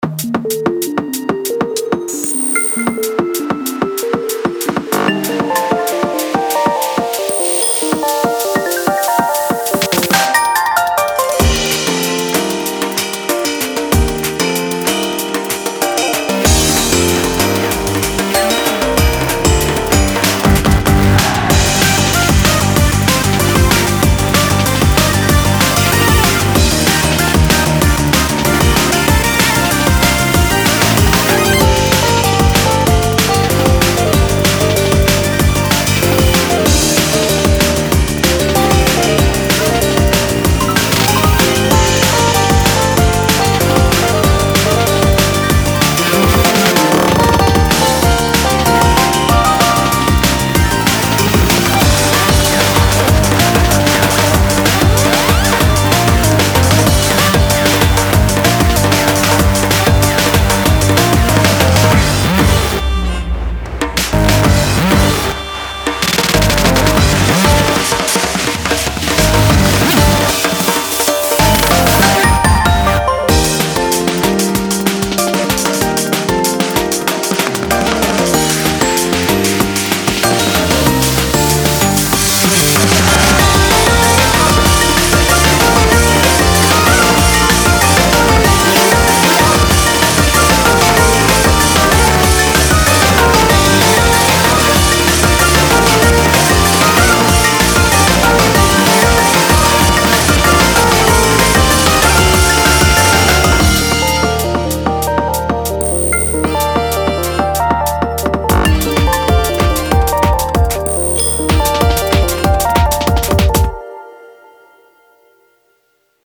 BPM90-190